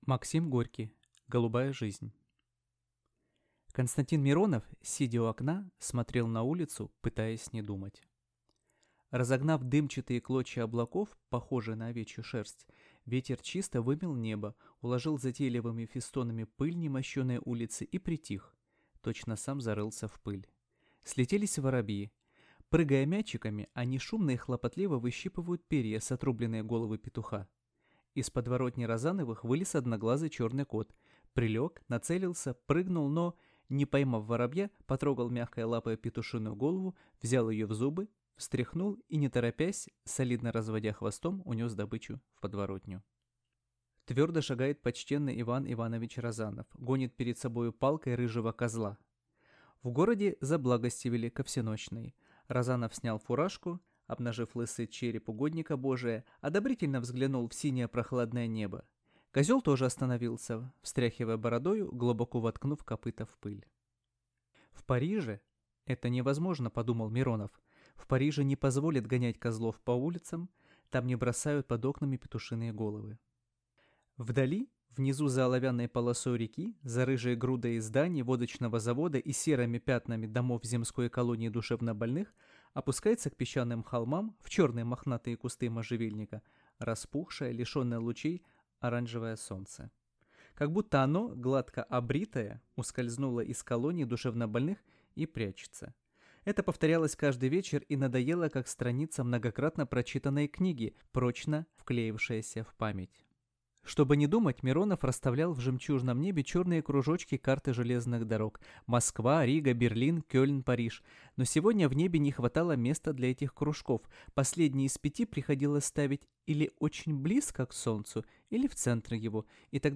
Аудиокнига Голубая жизнь | Библиотека аудиокниг